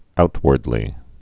(outwərd-lē)